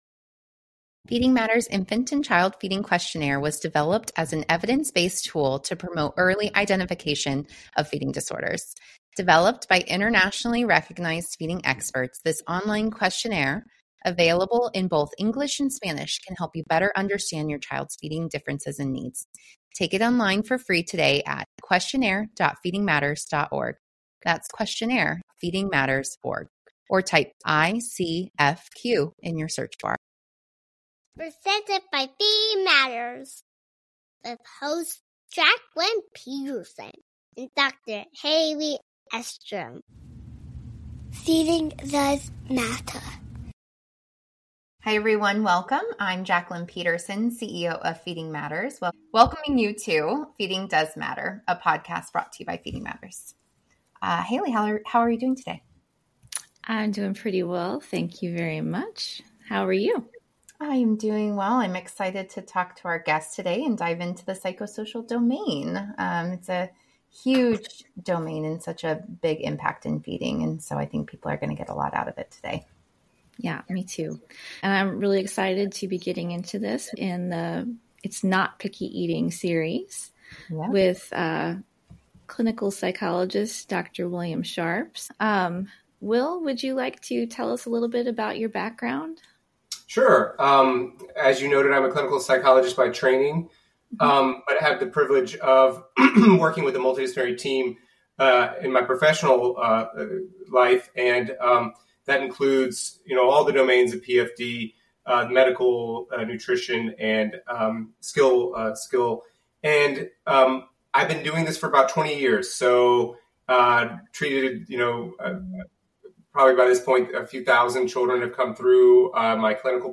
This conversation delves into the psychosocial aspects of pediatric feeding disorders, particularly focusing on the complexities of feeding challenges faced by children and their caregivers.